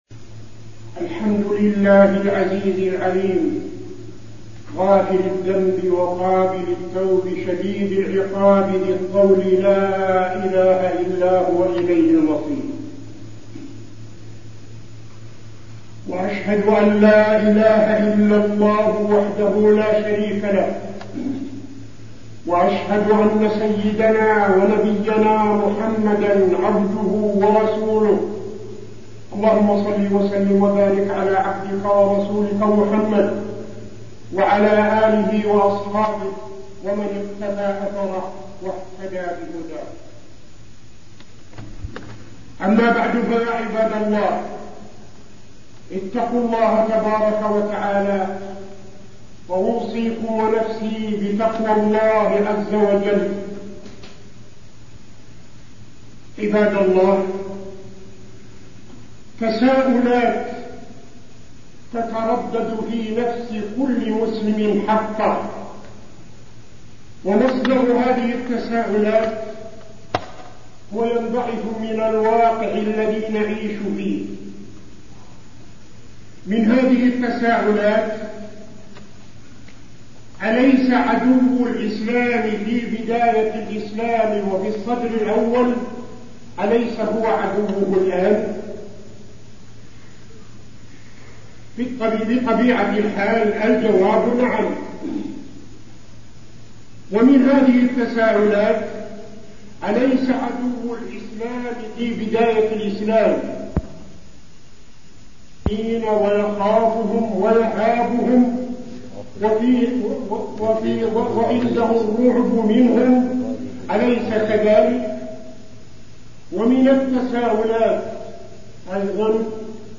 تاريخ النشر ١٨ رجب ١٤٠٦ هـ المكان: المسجد النبوي الشيخ: فضيلة الشيخ عبدالعزيز بن صالح فضيلة الشيخ عبدالعزيز بن صالح سبب ضعف المسلمين